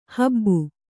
♪ habbu